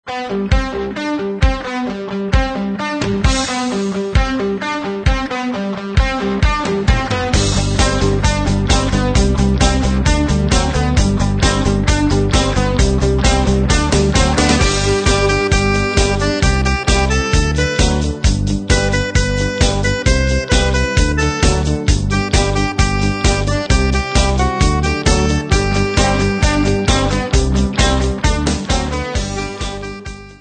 Besetzung: Akkordeon